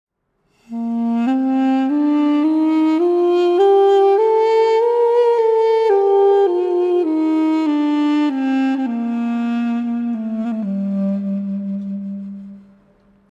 Дудук Bb
Тональность: Bb
Армянский дудук, изготовлен из абрикосового дерева.